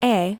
A-acorn-phoneme-AI.mp3